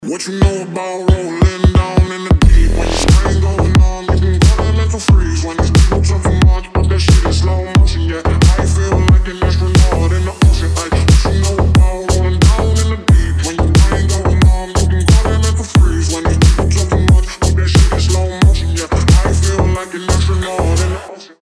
громкие рингтоны
Рингтоны техно
Deep house , Bass house , G-house , Мощные басы